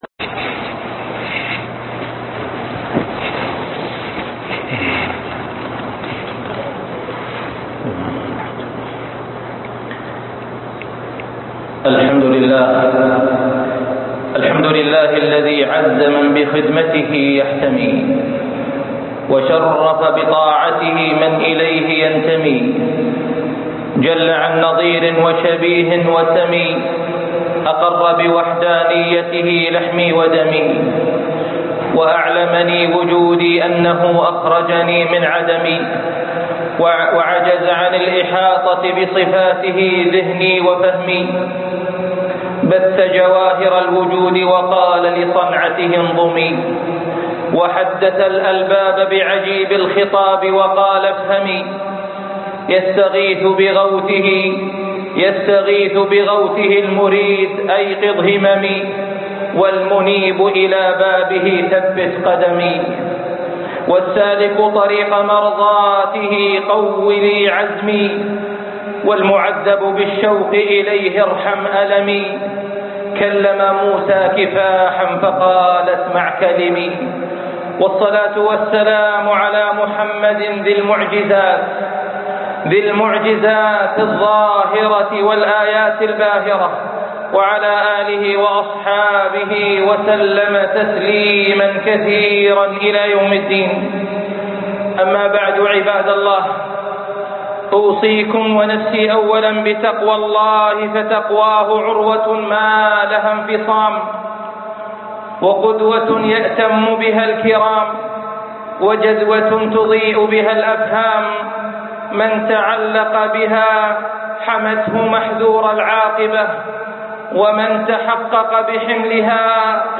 معركة الروح2 - خطب الجمعه